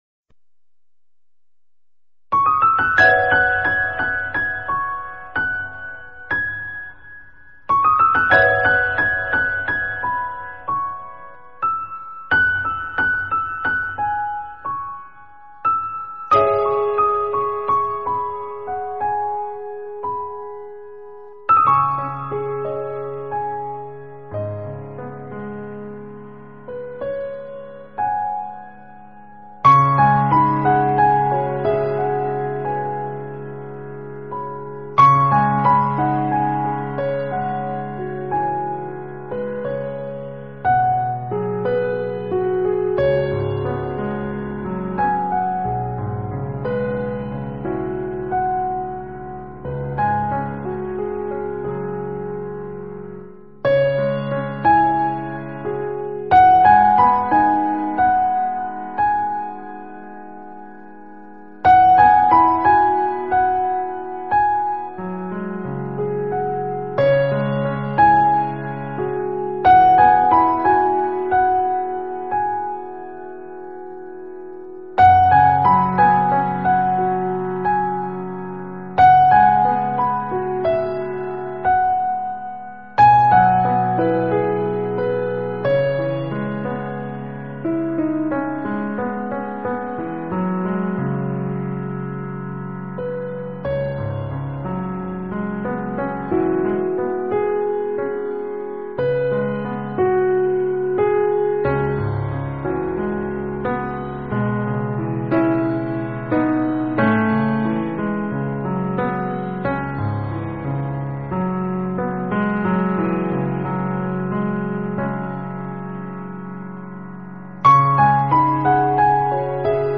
佛音 冥想 佛教音乐 返回列表 上一篇： 山楂树-口风琴--未知 下一篇： 在内心深处--佛教音乐(世界禅风篇